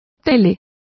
Complete with pronunciation of the translation of television.